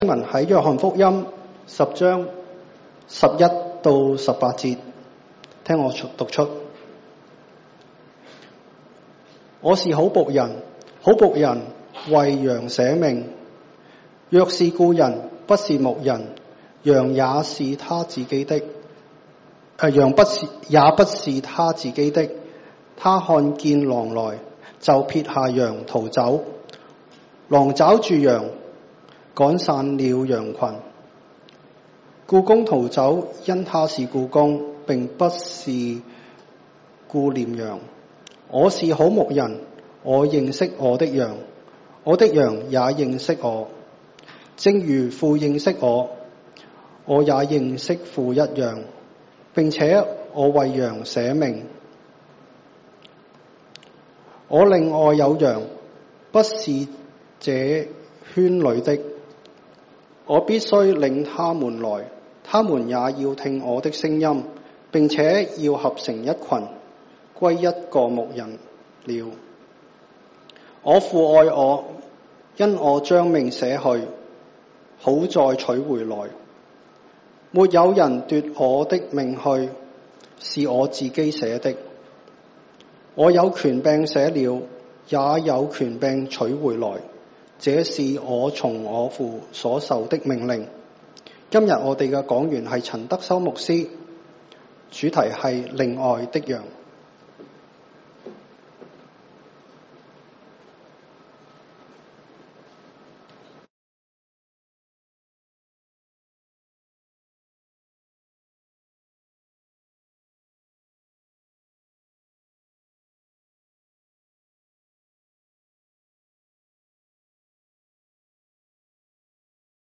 Cantonese 3rd Service, Chinese Category